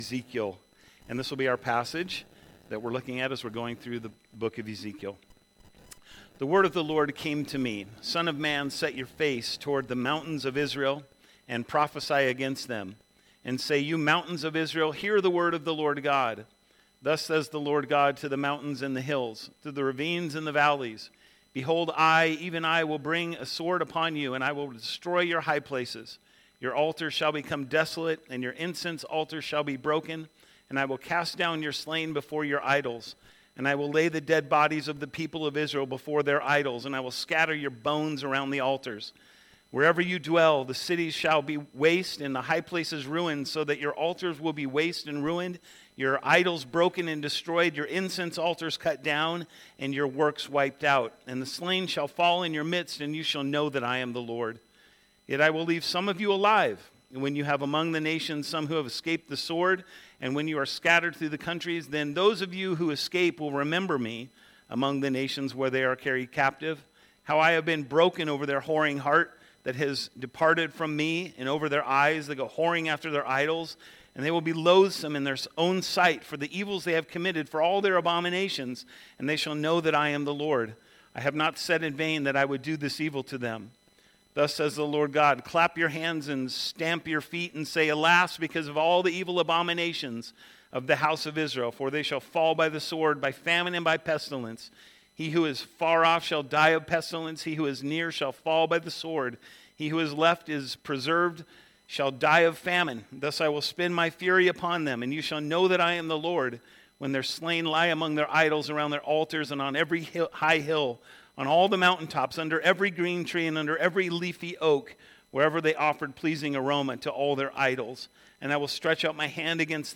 The newest sermons from Reformed Baptist Church of Northern Colo on SermonAudio.